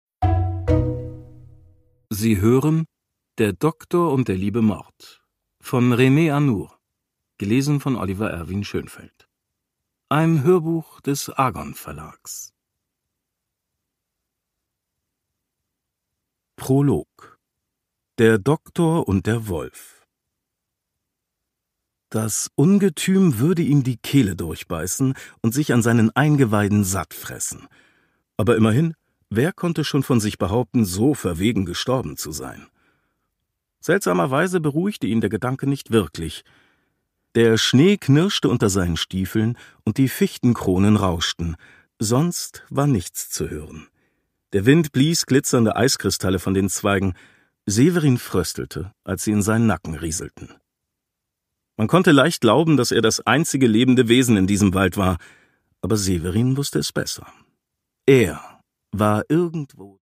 René Anour: Der Doktor und der liebe Mord - Ein Tierarzt-Krimi (Ungekürzte Lesung)
Produkttyp: Hörbuch-Download